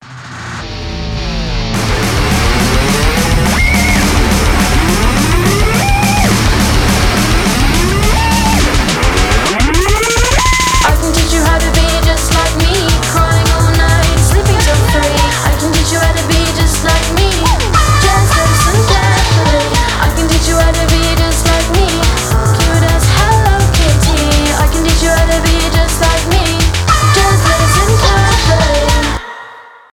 electronic rock
electropop